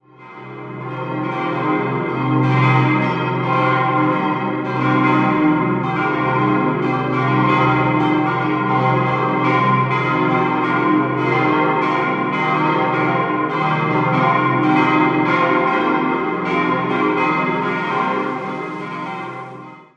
München-Isarvorstadt, Pfarrkirche St. Andreas Die Andreaskirche steht im Schlachthofviertel in der Isarvorstadt. Das Gotteshaus entstand im Jahr 1953 als eines der ersten modernen Gotteshäuser Münchens nach dem Zweiten Weltkrieg. 5-stimmiges Geläut: c'-es'-f'-g'-b' Alle Glocken wurden von 1953 vom Bochumer Verein für Gussstahlfabrikation gegossen.